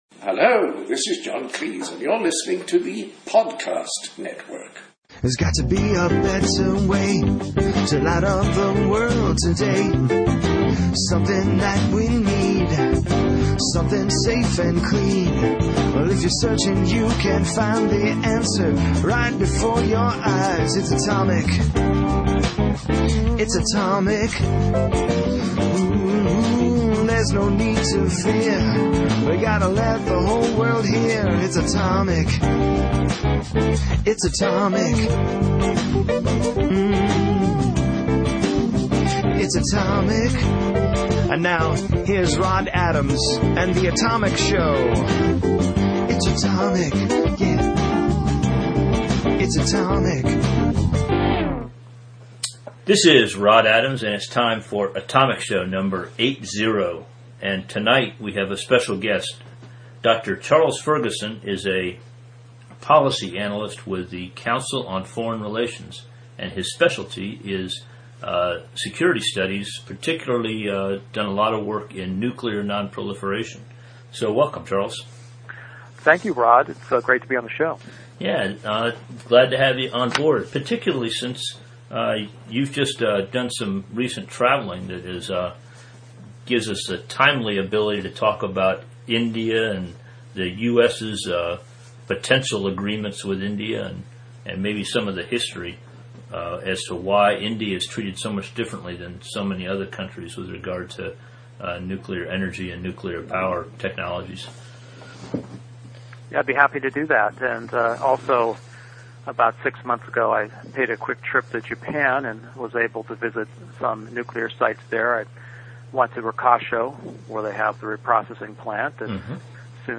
Interview
We engaged in a wide ranging discussion.